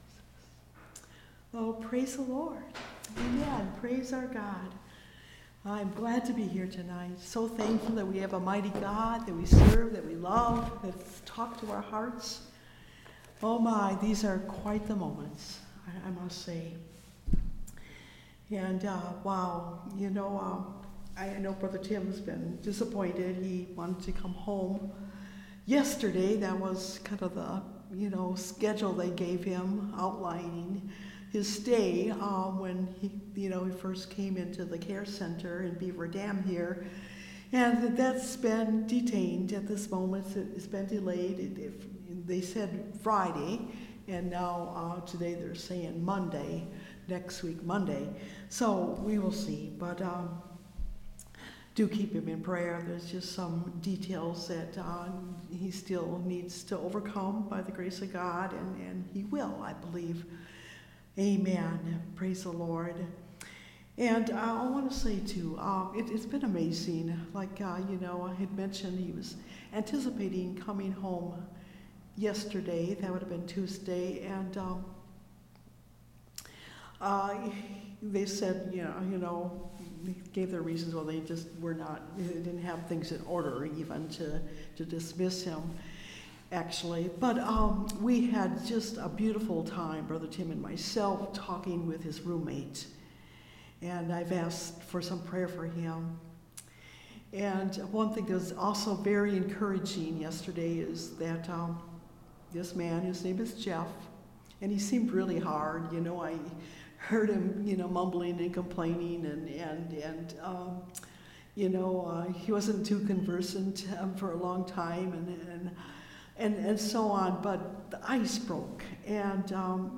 When My Heart Is Overwhelmed (Message Audio) – Last Trumpet Ministries – Truth Tabernacle – Sermon Library